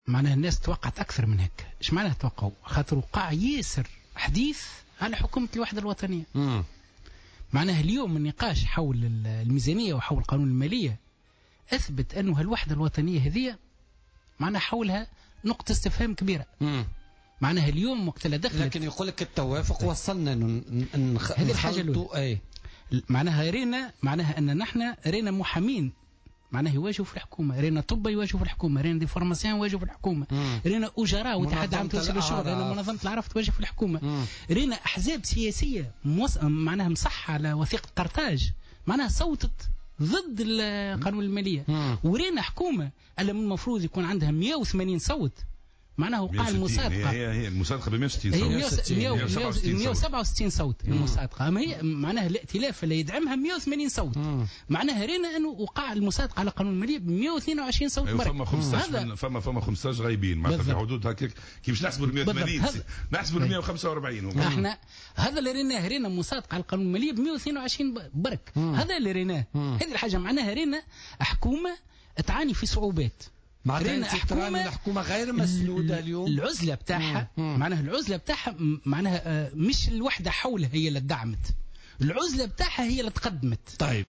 وأوضح بن عانس، ضيف برنامج "بوليتيكا" اليوم الثلاثاء أن النقاش الحاصل حول قانون المالية أثبت وجود نقاط استفهام حول الوحدة الوطنية ، مشيرا إلى أن أحزاب سياسية أمضت على وثيقة قرطاج صوتت ضدّ القانون.